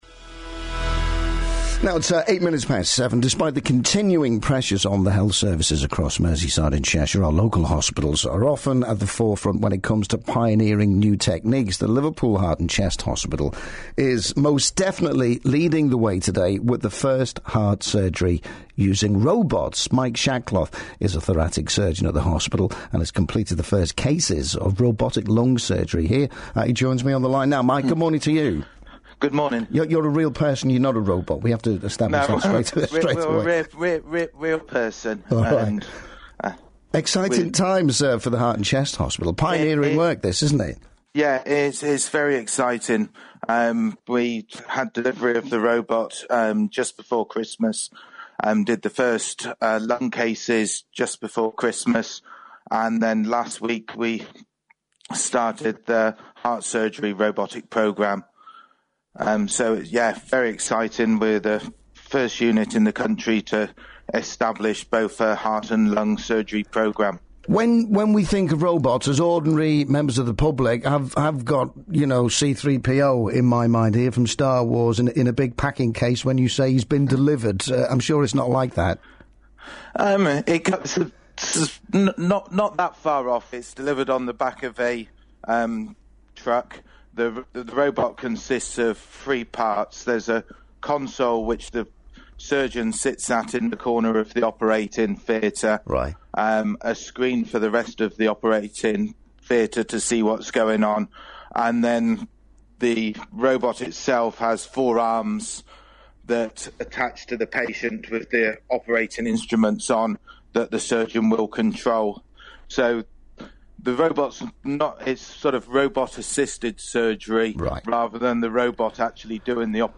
speaking to BBC Radio Merseyside about the robotic heart and lung surgery programme